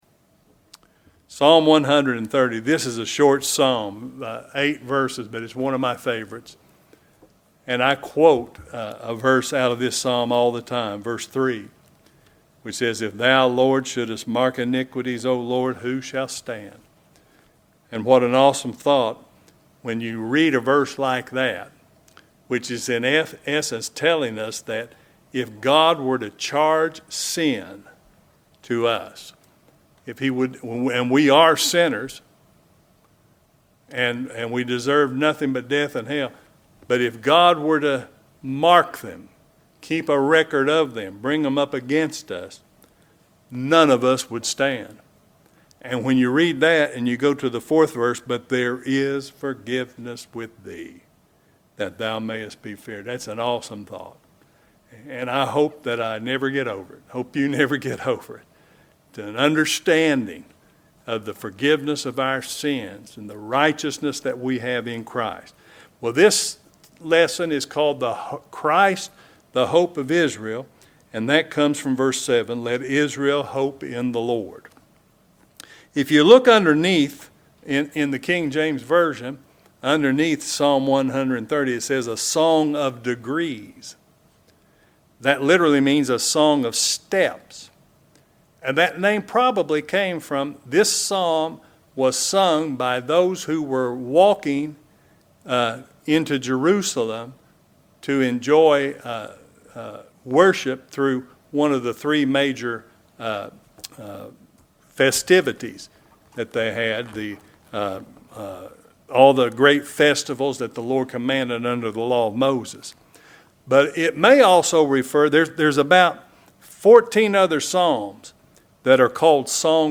Christ, the Hope of Israel | SermonAudio Broadcaster is Live View the Live Stream Share this sermon Disabled by adblocker Copy URL Copied!